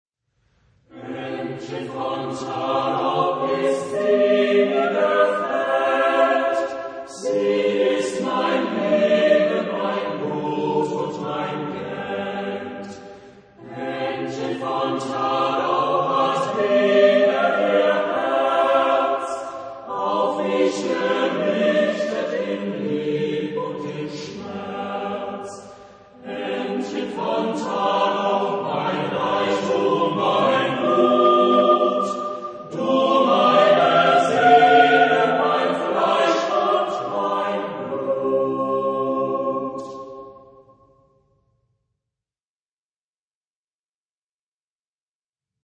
Genre-Style-Forme : Arrangement choral ; Folklore ; Profane
Type de choeur : SATB  (4 voix mixtes )
Tonalité : do majeur